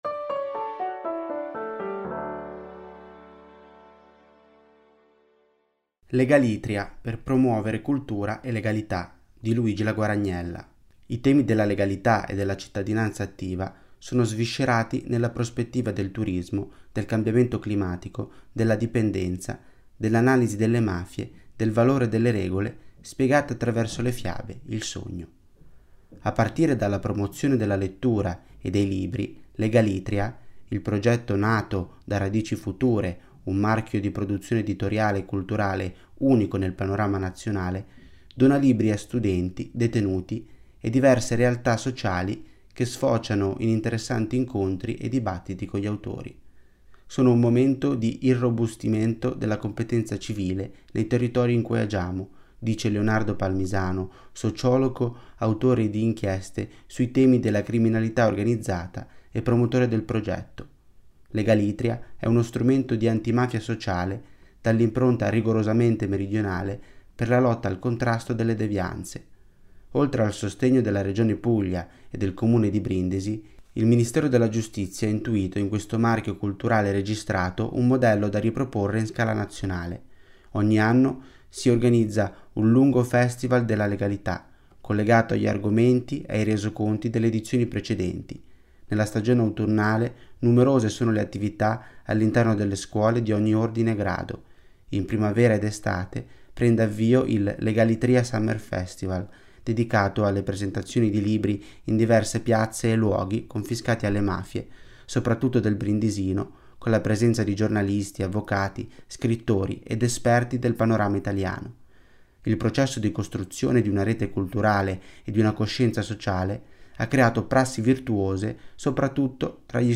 Per ogni numero, ci sarà una selezione di 10 articoli letti dai nostri autori e collaboratori.
Al microfono, i nostri redattori e i nostri collaboratori.